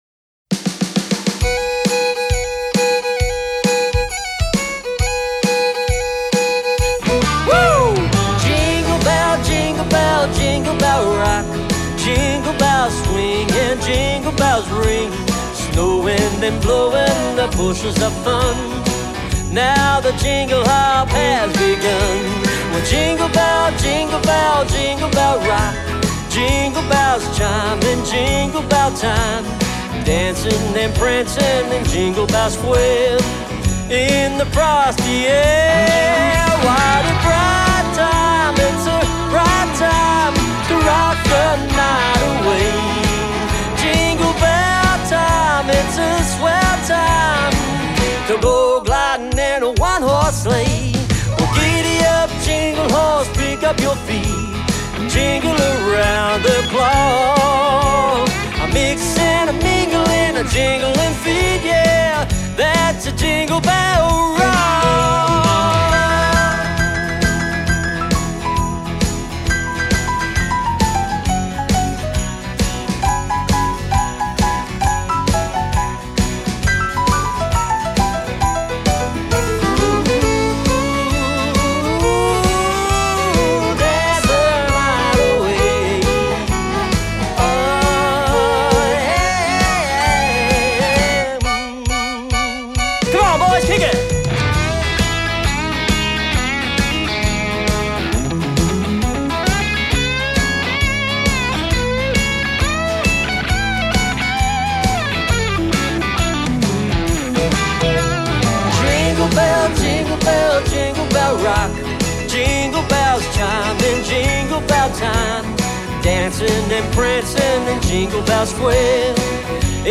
natural singing talent
driving upbeat rendition